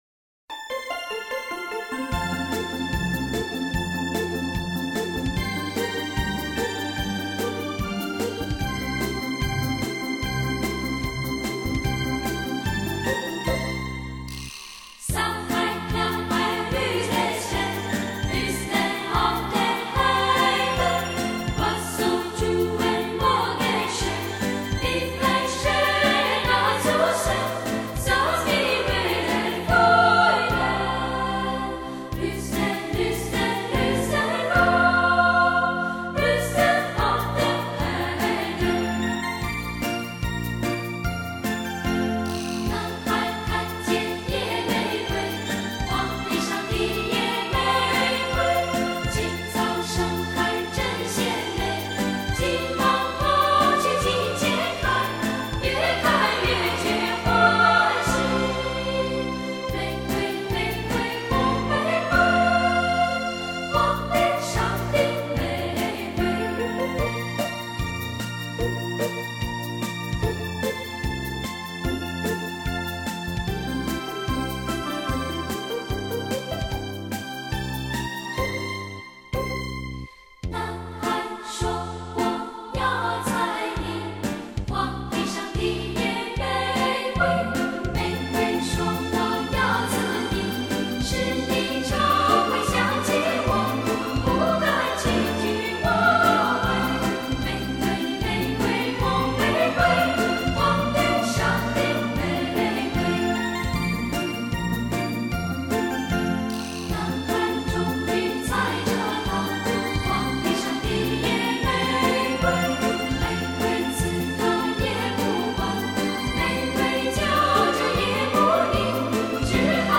以优质的童声合唱占领了爱乐人的心
完美的录音留住了漂亮的行腔